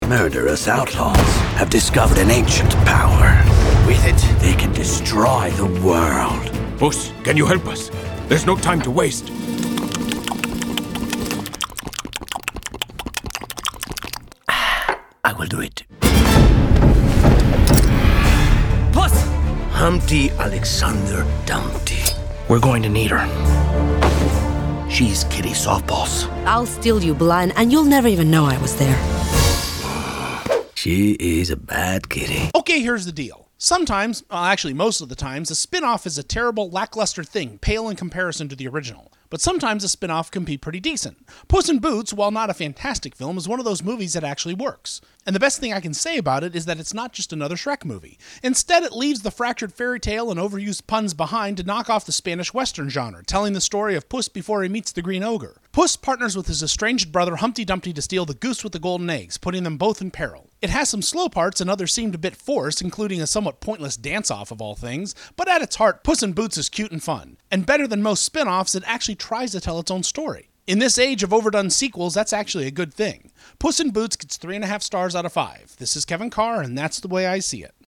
'Puss in Boots' Movie Review